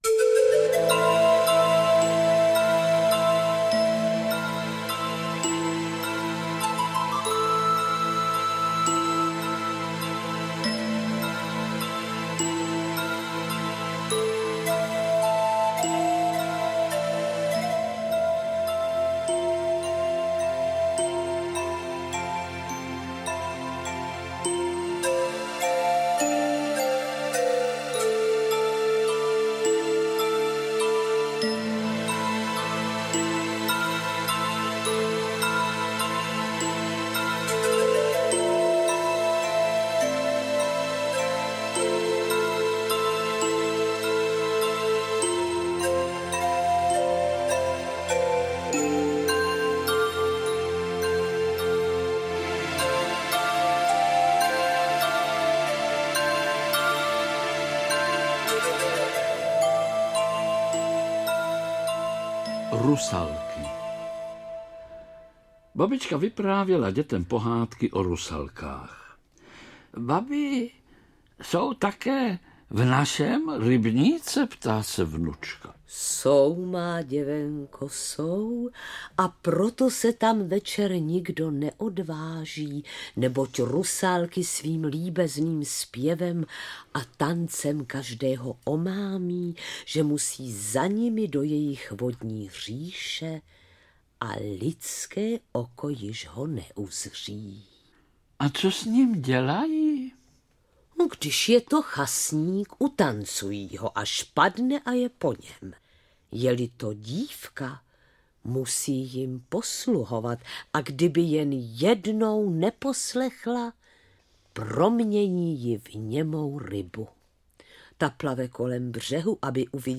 • AudioKniha ke stažení O rusalkách, vodníkovi a světýlkách. Pohádky ze Šumavy II